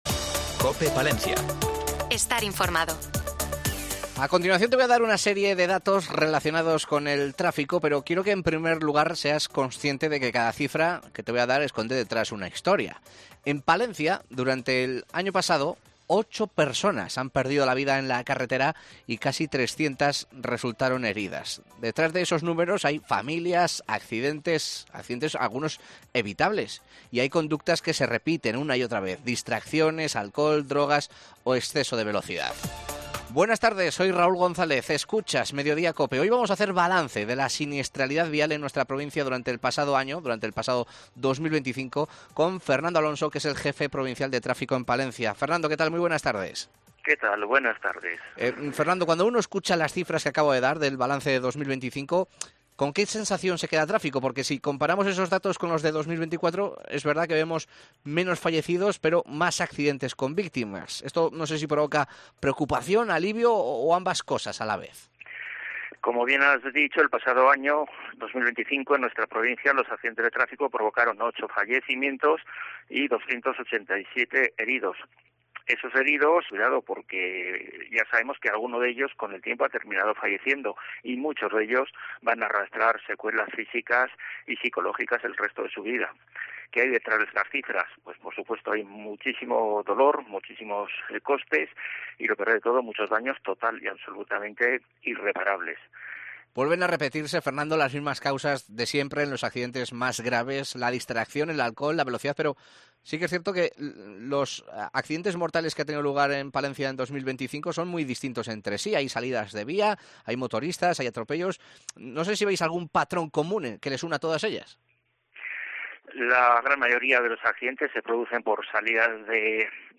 Fernando Alonso, jefe provincial de Tráfico en Palencia, hace balance de 2025
En una entrevista en el programa 'Mediodía COPE' en Palencia, el jefe provincial de Tráfico, Fernando Alonso, ha desgranado el balance anual, que deja 8 personas fallecidas y 287 heridas en las carreteras de la provincia.